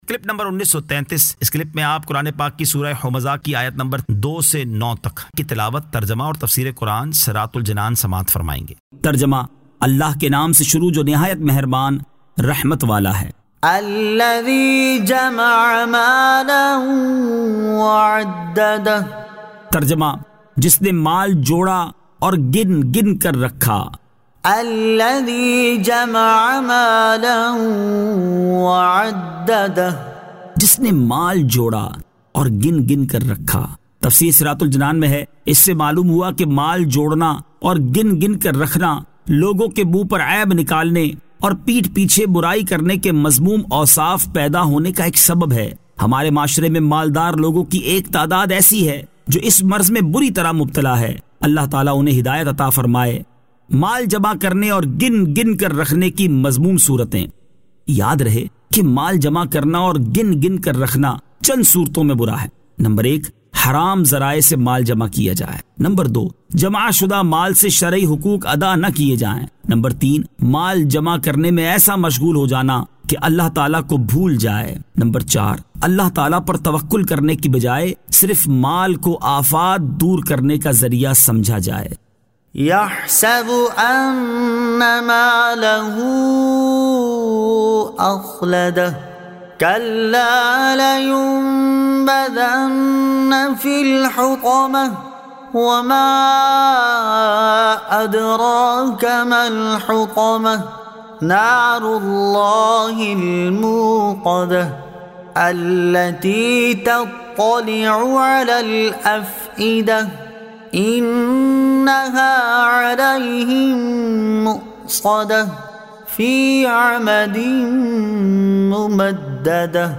Surah Al-Humazah 02 To 09 Tilawat , Tarjama , Tafseer